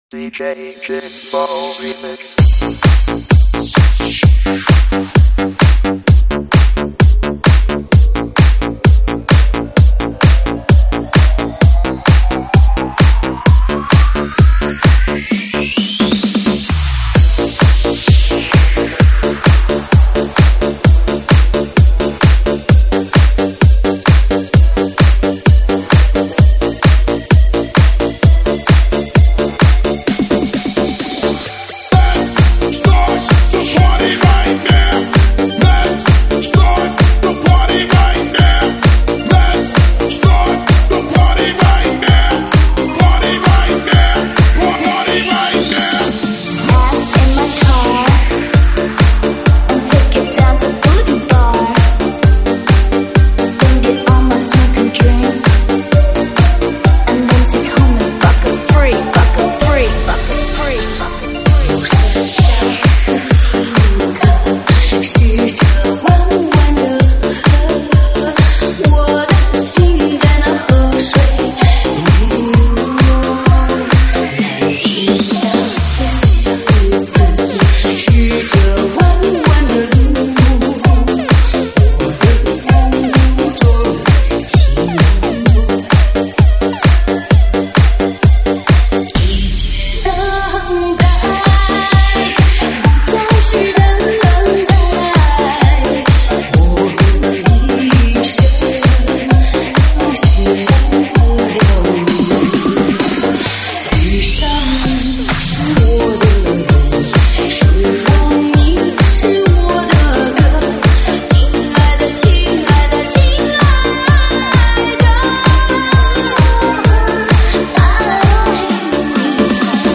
舞曲类别：中文舞曲